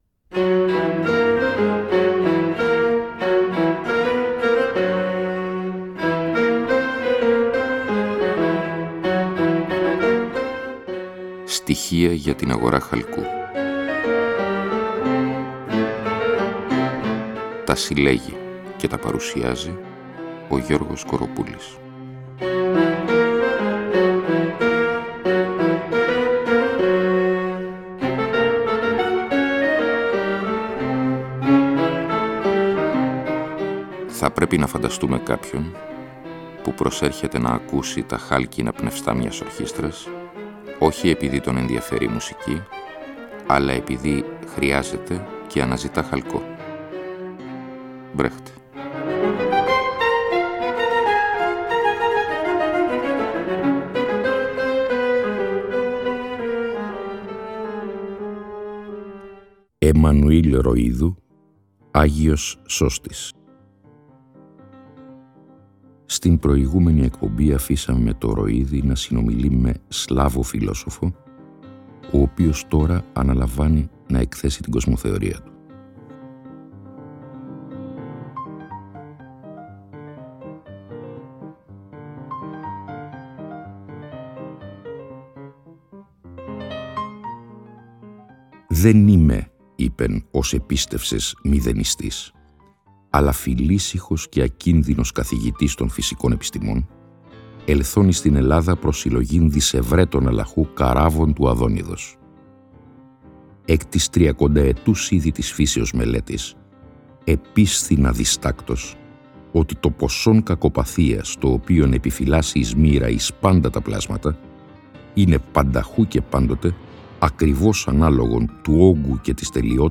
Εκπομπή λόγου. Ακούγονται, ερμηνεύονται και συγκρίνονται με απροσδόκητους τρόπους κείμενα λογοτεχνίας, φιλοσοφίας, δοκίμια κ.λπ. Η διαπλοκή του λόγου και της μουσικής αποτελεί καθ εαυτήν σχόλιο, είναι συνεπώς ουσιώδης.